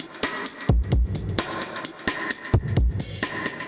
Index of /musicradar/dub-designer-samples/130bpm/Beats
DD_BeatD_130-01.wav